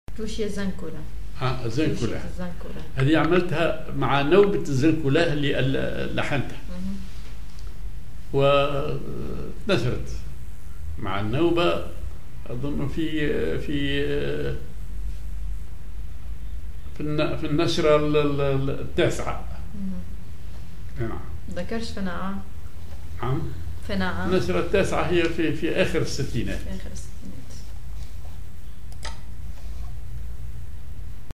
Maqam ar زنكولاه
هي ضمن نوبة الزنكولاه genre توشية